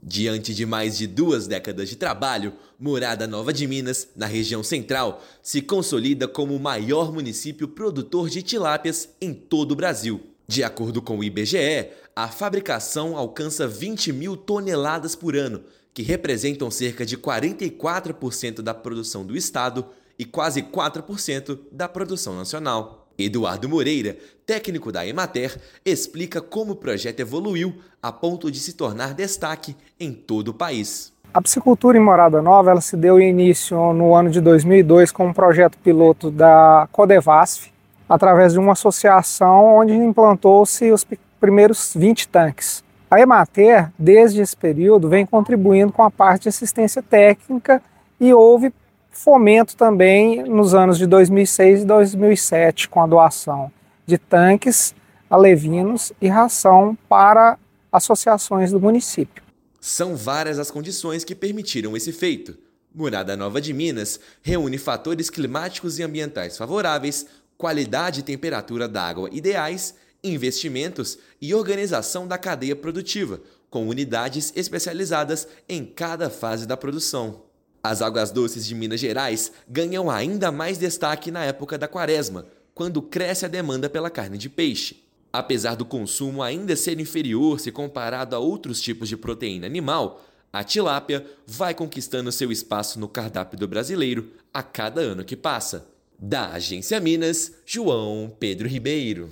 Agência Minas Gerais | [RÁDIO] Morada Nova de Minas, na região Central, é o maior município produtor de tilápias do Brasil
Trabalho, com mais de duas décadas, conta com assistência técnica da Emater-MG. Ouça matéria de rádio.